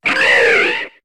Cri de Gueriaigle dans Pokémon HOME.